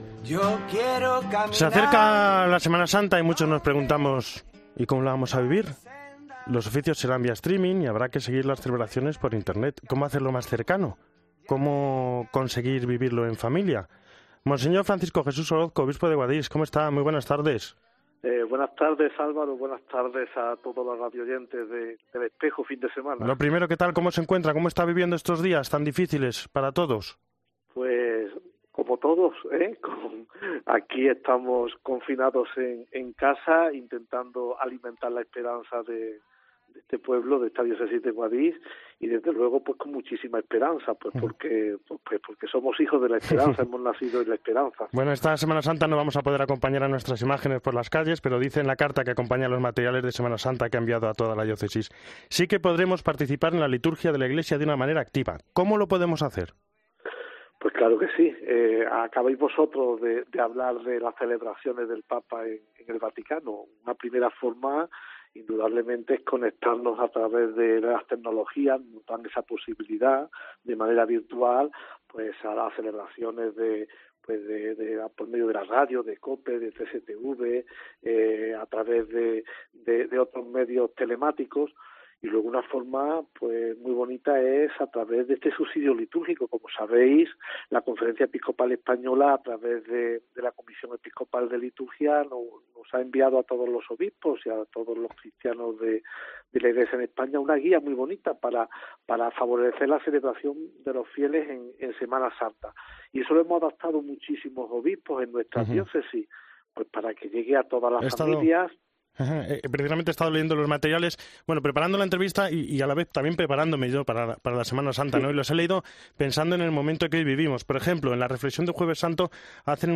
Monseñor Francisco Jesús Orozco repasa en COPE por qué es importante el subsidio litúrgico durante estos días de Semana Santa
Por ello, y desde casa, Monseñor Francisco Jesús Orozco, obispo de Guadix, repasa la importancia del subsidio litúrgico para esta semana.